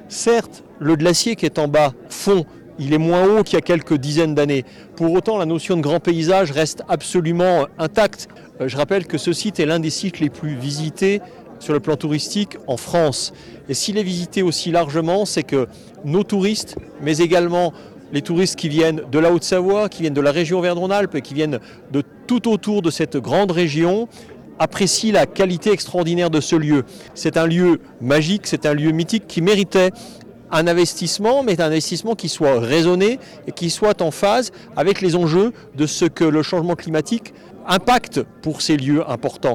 Néanmoins, Eric Fournier, le maire de Chamonix, croit encore en l’attractivité de ce lieu.